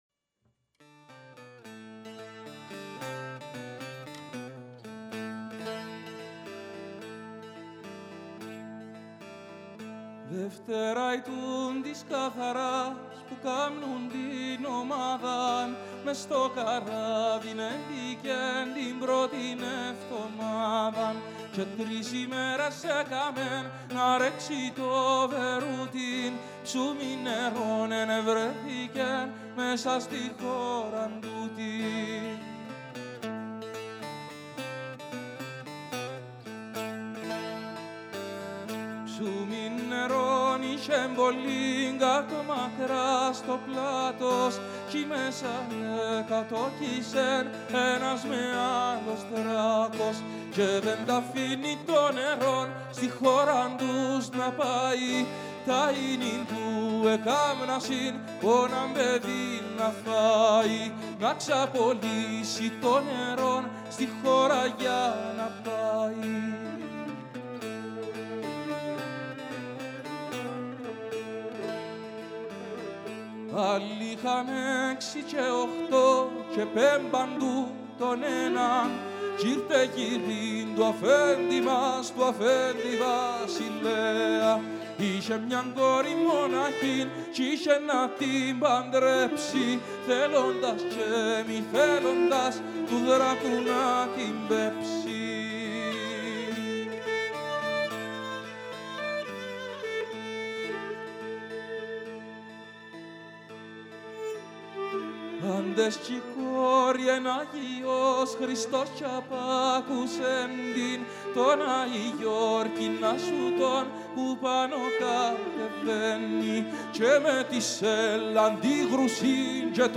“Τ’ Άη Γιωρκού” παραδοσιακό Κύπρου, επεξεργασία για Φωνή και Ορχήστρα
Σόλο βιολί